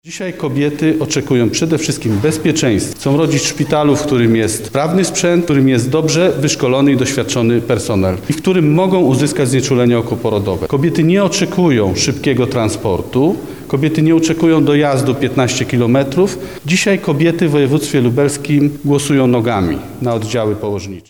Krzysztof Bojarski– mówi Krzysztof Bojarski, poseł platformy obywatelskiej